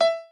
b_piano1_v100l16o6e.ogg